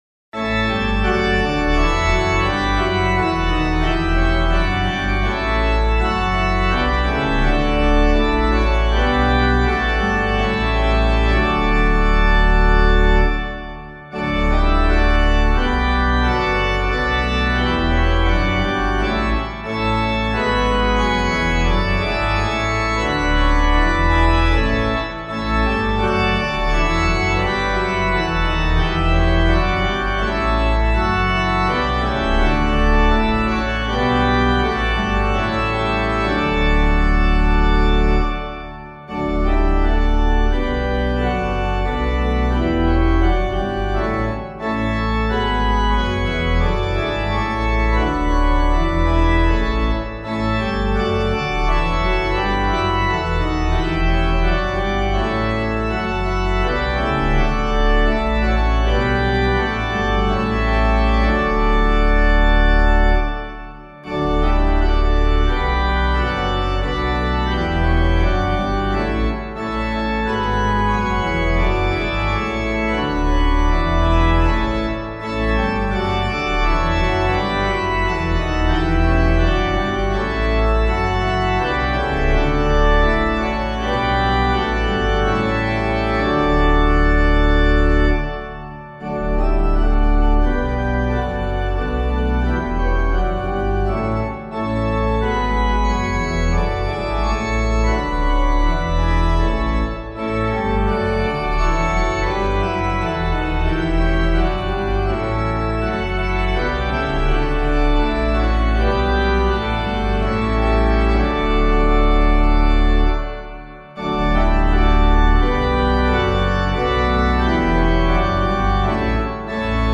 harmonized by Johann Sebastian Bach, 1685-1750.
organ